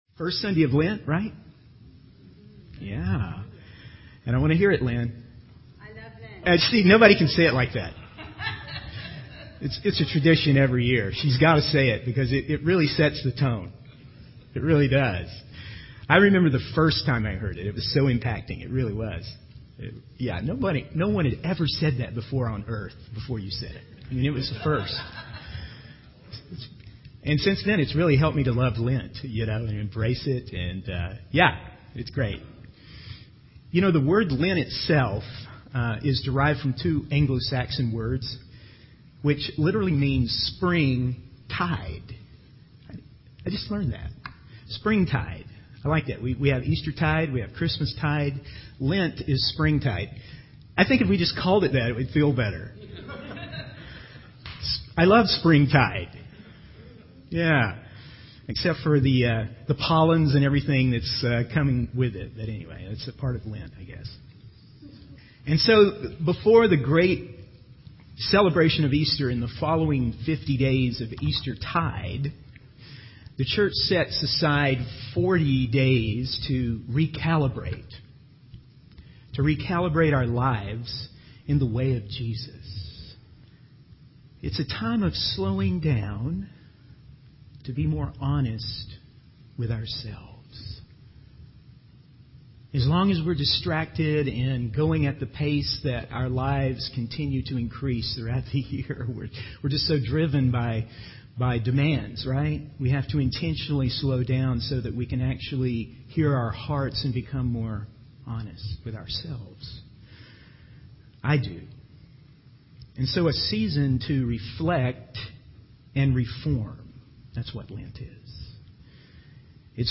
In this sermon, the speaker discusses the season of Lent and its emphasis on following Jesus. The sermon focuses on the first four verses of Matthew chapter 6, which talk about practicing righteousness in secret rather than for the approval of others. The speaker highlights the importance of embodying the ways of Jesus in the church and being a visible representation of God's kingdom on earth.